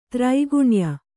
♪ traiguṇya